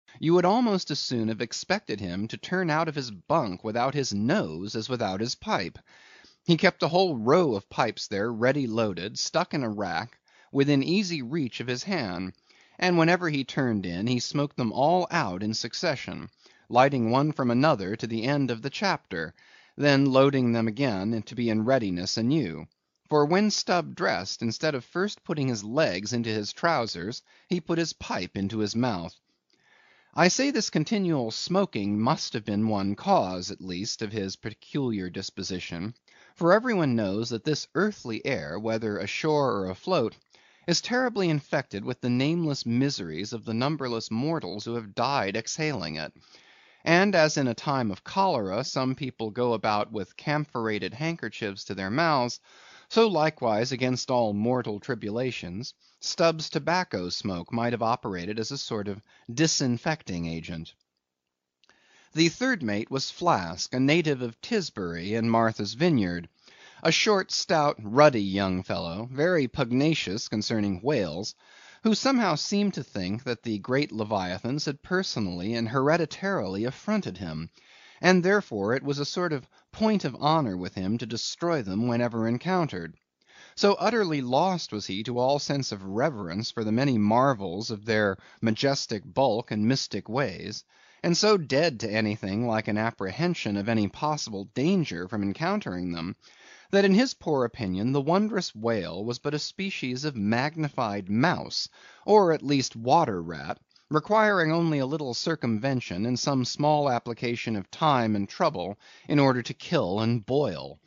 英语听书《白鲸记》第348期 听力文件下载—在线英语听力室